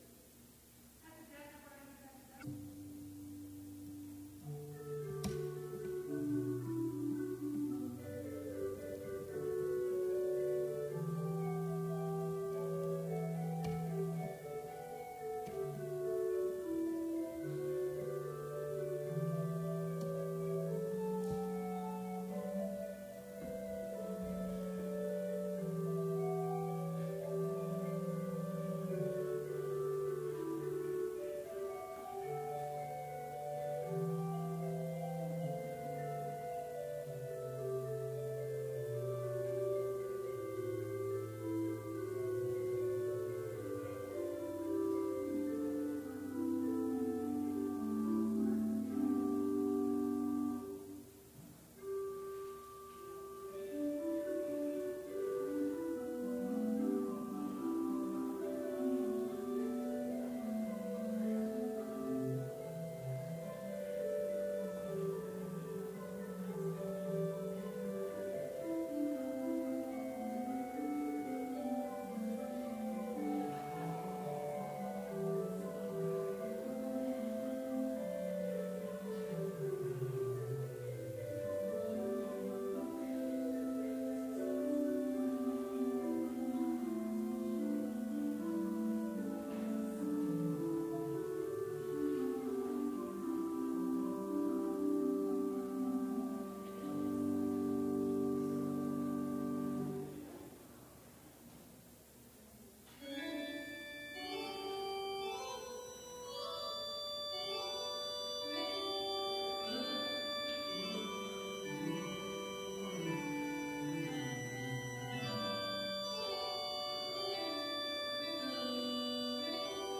Complete service audio for Chapel - January 14, 2019